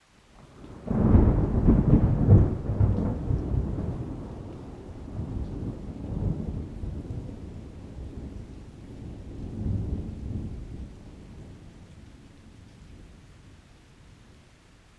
thunder-1.mp3